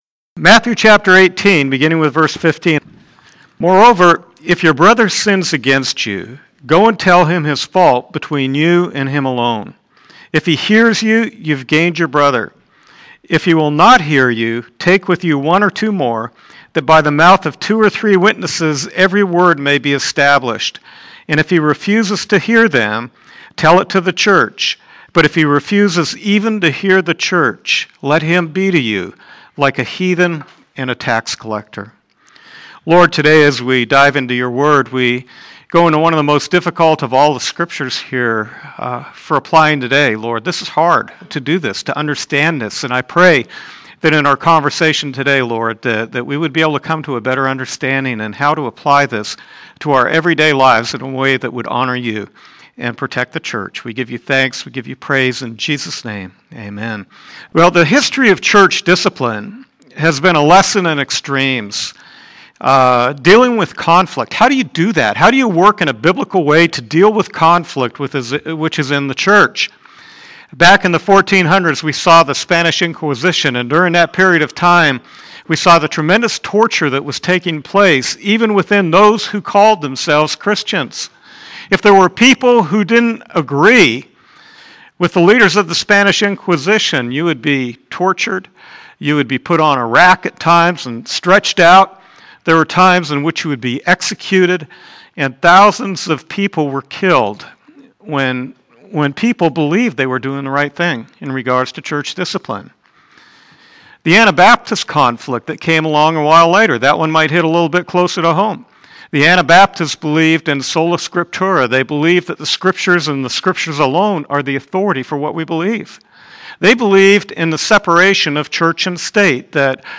Sermon Podcast “Conflict in the Church” January 18, 2015